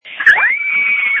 • When you call, we record you making sounds. Hopefully screaming.
You might be unhappy, terrified, frustrated, or elated. All of these are perfectly good reasons to call and record yourself screaming.